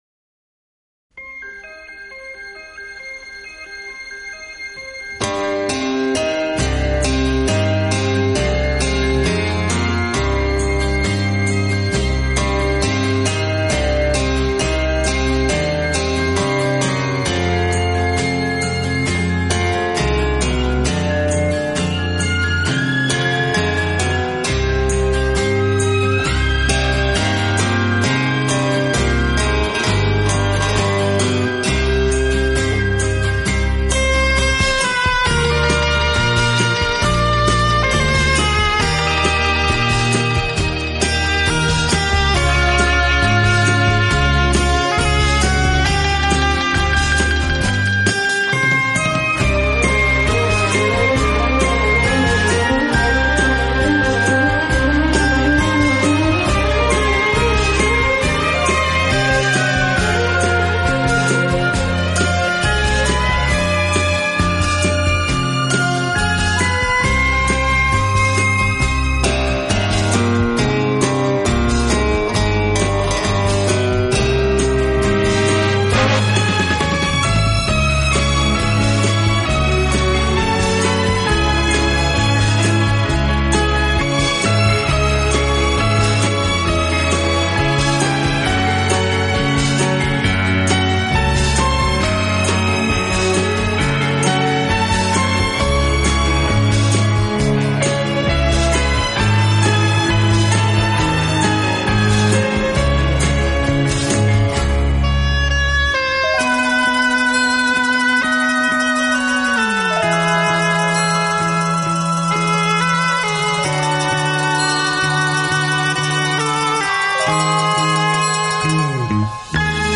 音乐类型：Instrumental, Easy Listening
其风格清新明朗，华丽纯朴，从不过分夸张。